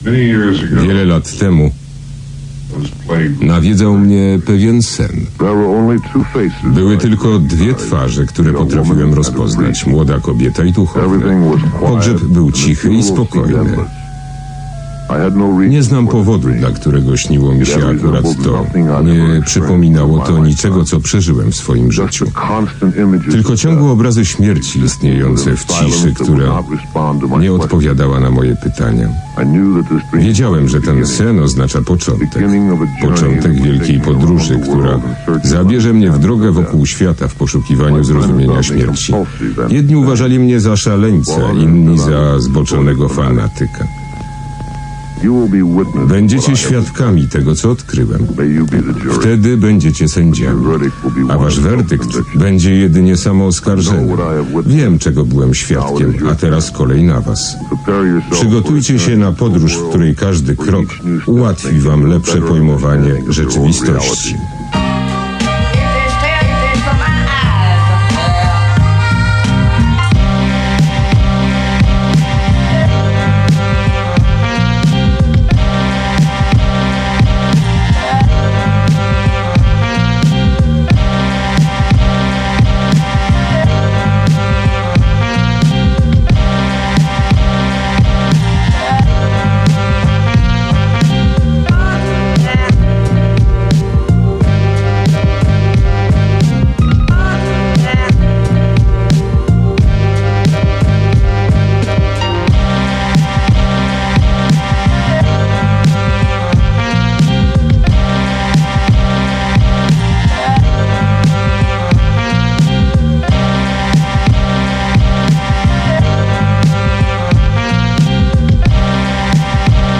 produkcje okołorapowe mające na celu szerzenie niepokoju.